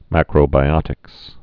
(măkrō-bī-ŏtĭks)